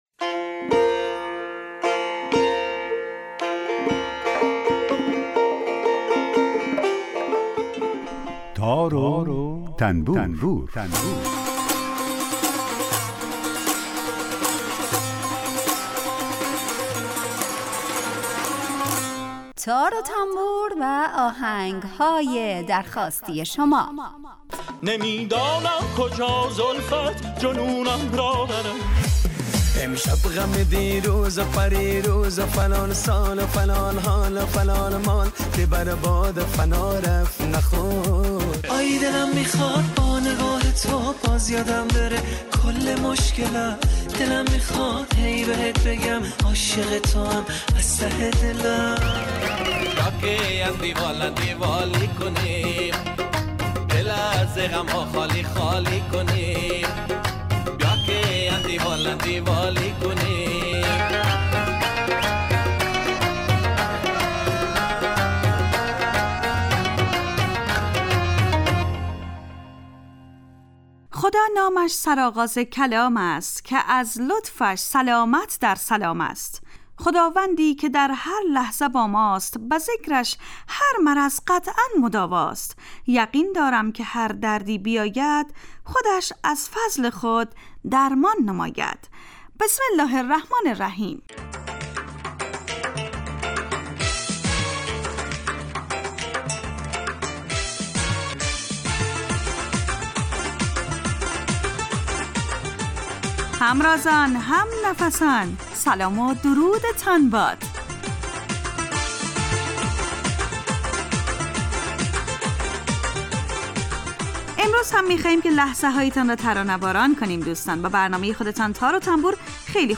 برنامه تار و تنبور هر روز از رادیو دری به مدت 30 دقیقه برنامه ای با آهنگ های درخواستی شنونده ها کار از گروه اجتماعی رادیو دری. در این برنامه هر یه آیتم به نام در کوچه باغ موسیقی گنجانده شده که به معرفی مختصر ساز ها و آلات موسیقی می‌پردازیم و یک قطعه بی کلام درباره همون ساز هم نشر میکنیم